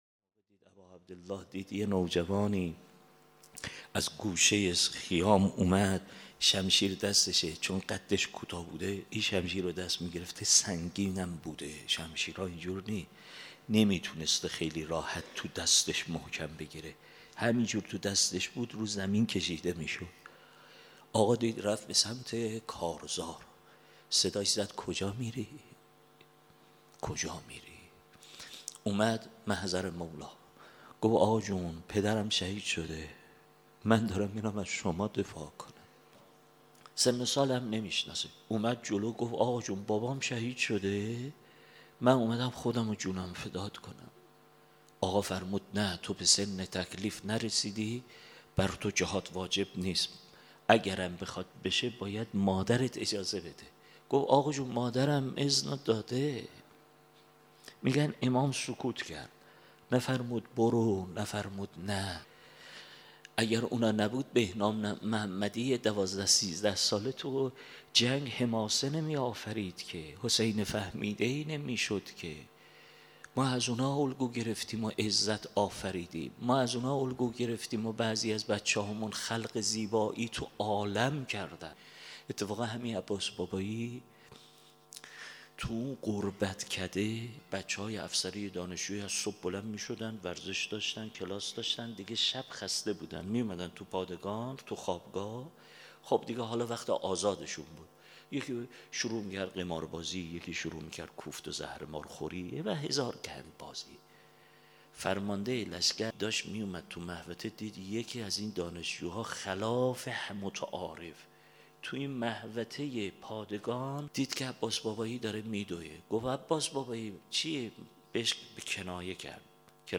سخنرانی | روضه حضرت قاسم در روز عاشورا و اذن گرفتن از امام حسین(ع)